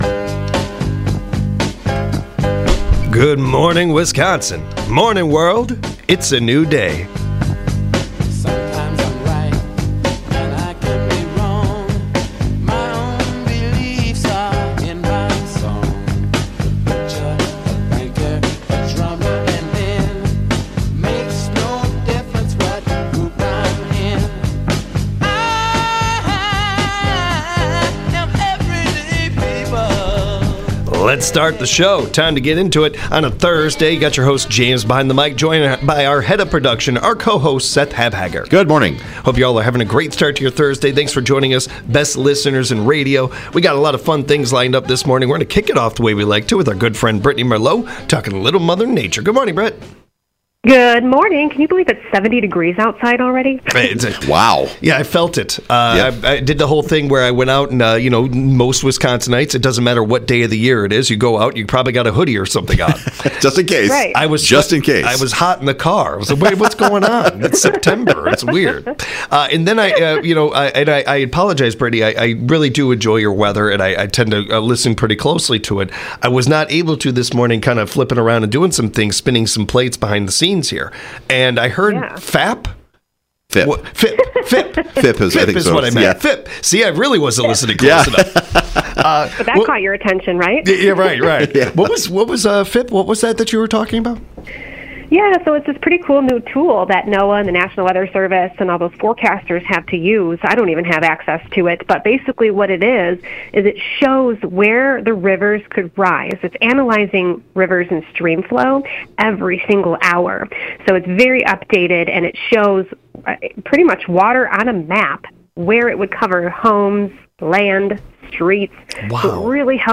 Line cutting stories are universal, and now viral . The guys discuss all their thoughts about line cutting and other social contracts.